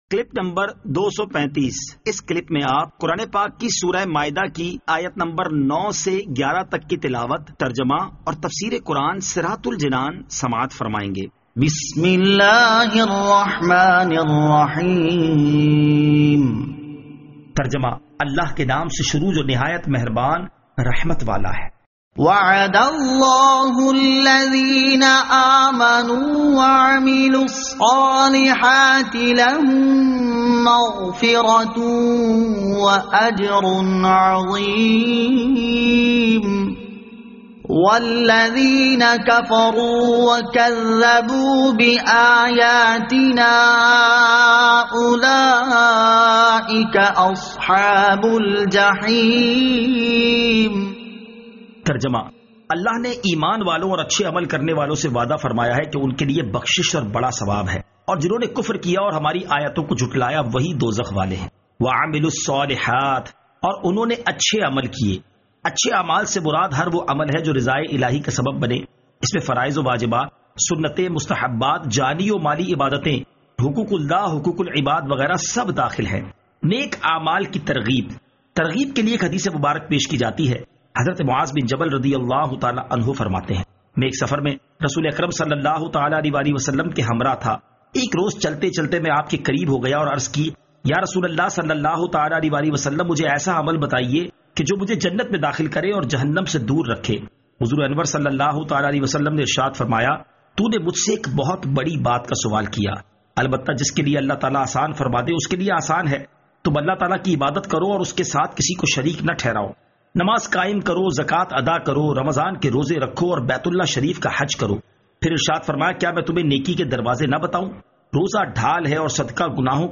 Surah Al-Maidah Ayat 09 To 11 Tilawat , Tarjama , Tafseer